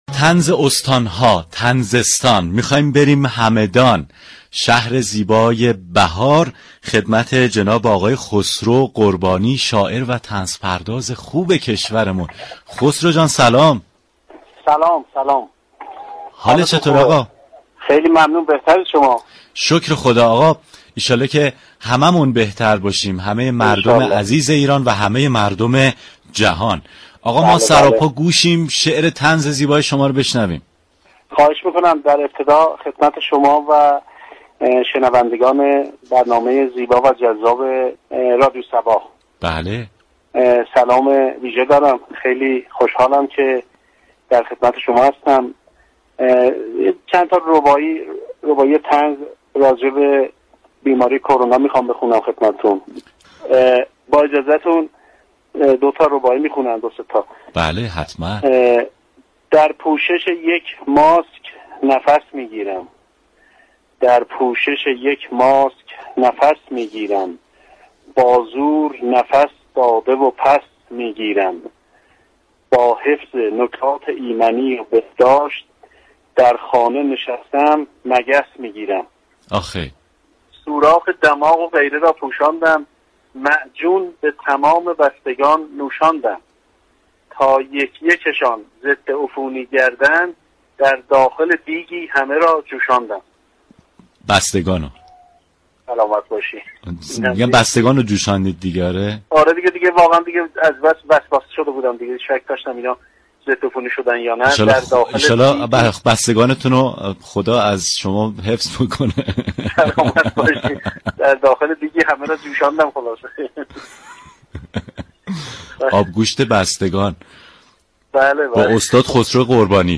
شنونده گفتگوی رادیو صبا